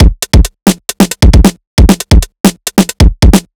Bounce Linn Break 2 135.wav